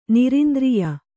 ♪ nirindriya